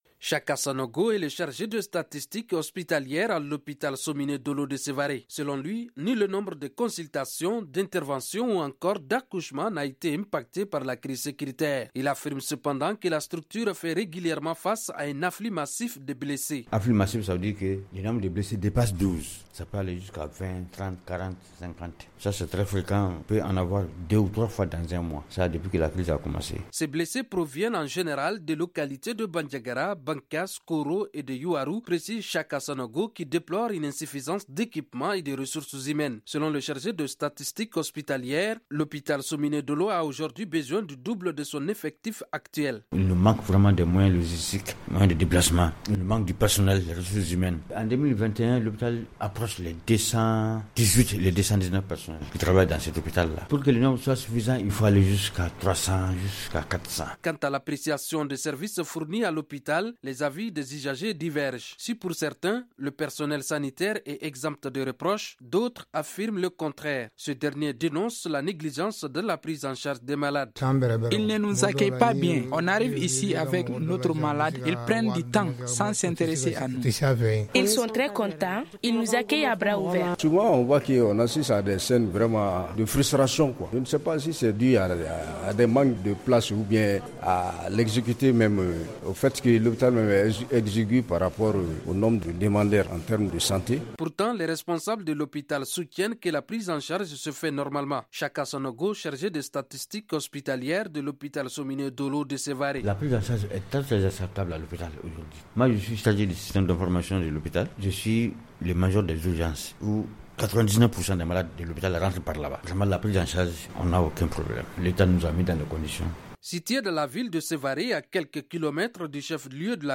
Magazine en français: Télécharger